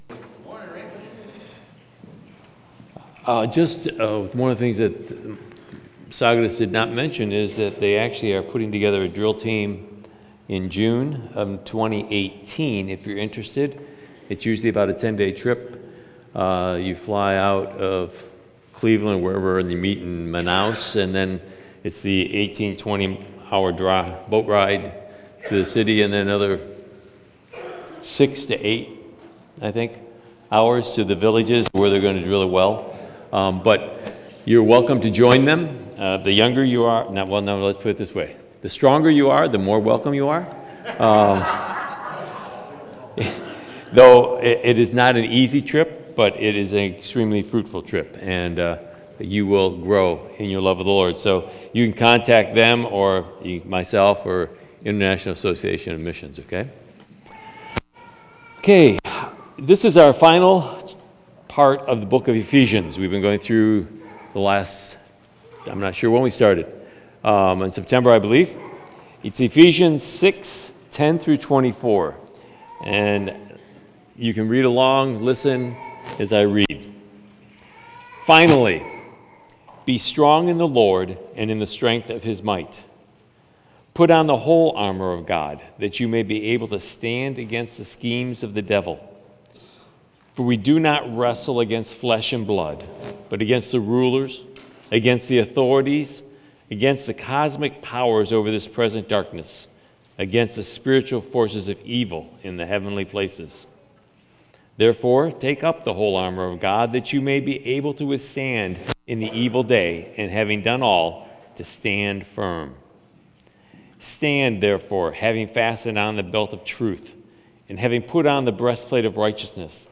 November 26 Sermon | A People For God